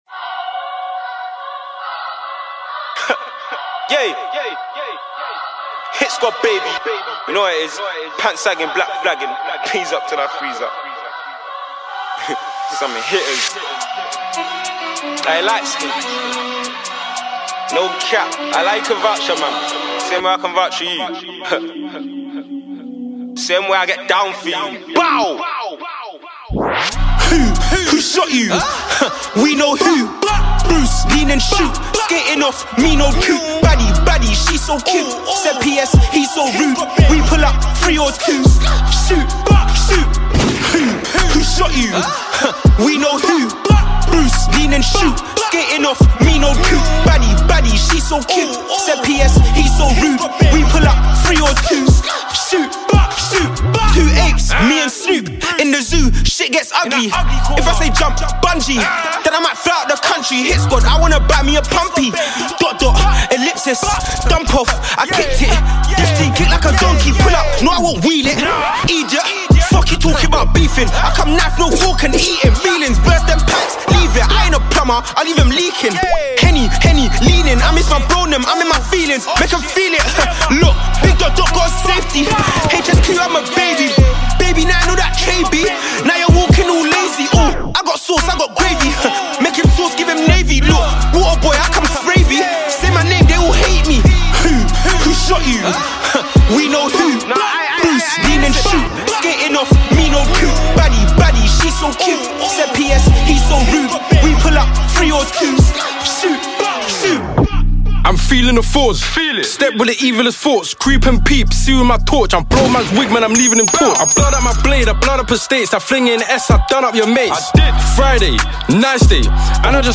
for me it's uk drill